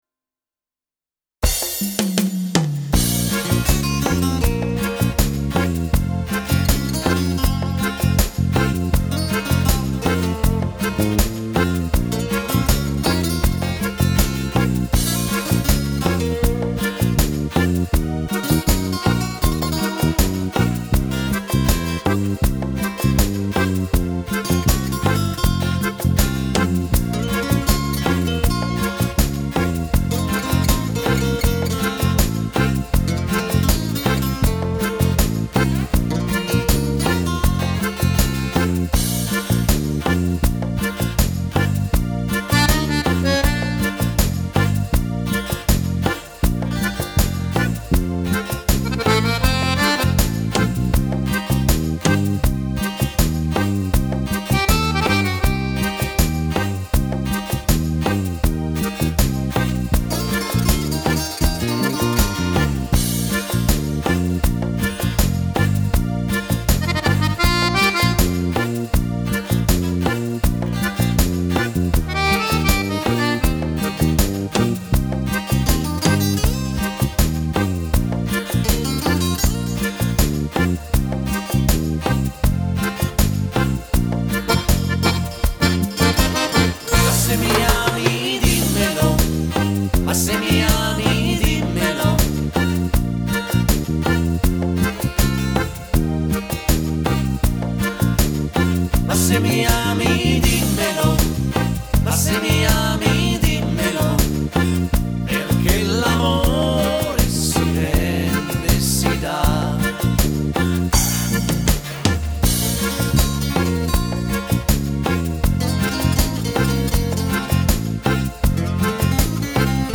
Bajon lento
Uomo